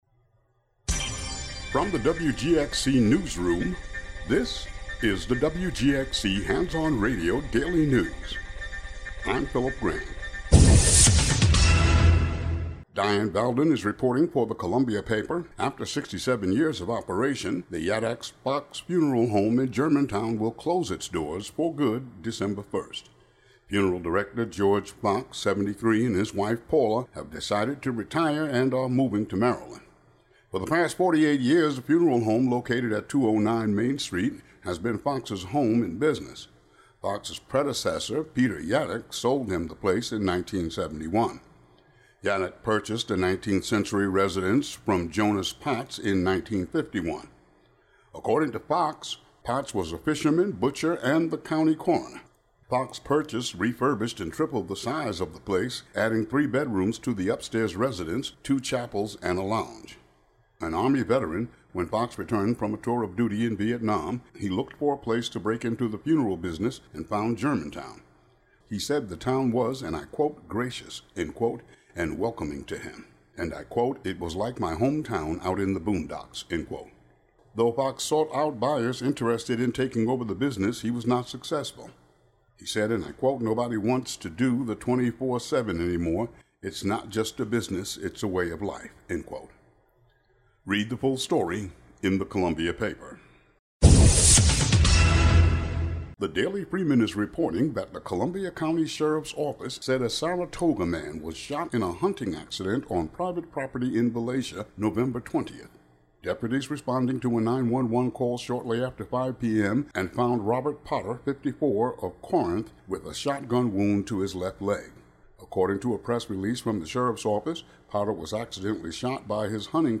"All Together Now!" is a daily news show brought t...